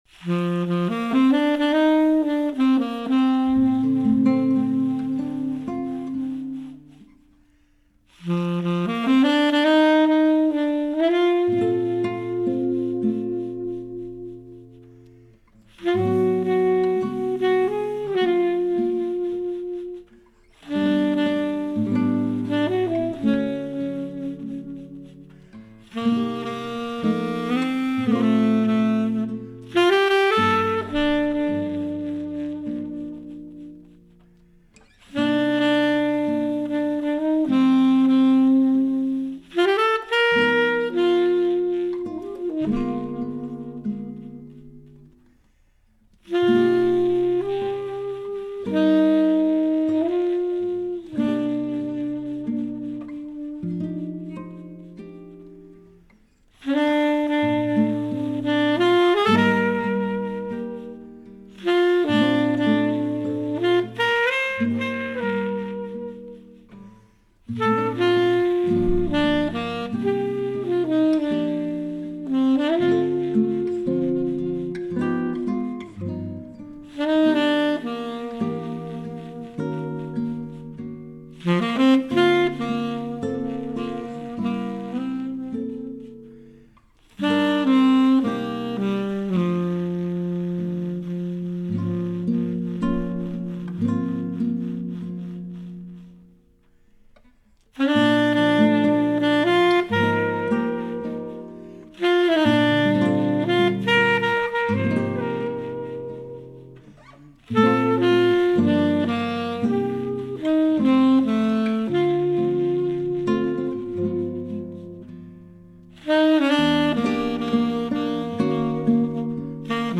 saxophone
acoustic bass
guitar
drums